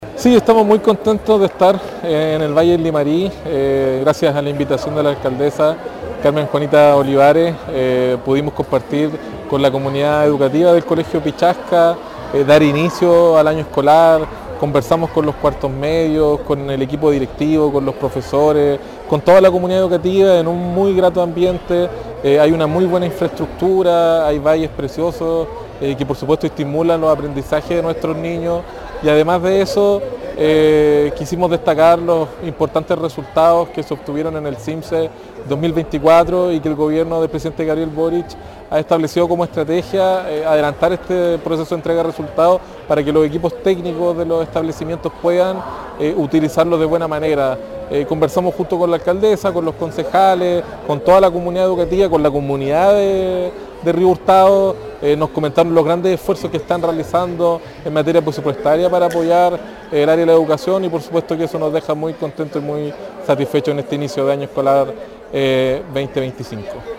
En el Colegio Pichasca, de la comuna de Río Hurtado, la Municipalidad inauguró el año escolar 2025.
Mientras que el seremi de Educación Nicolás Pérez comentó las virtudes de la enseñanza en el Colegio Pichasca.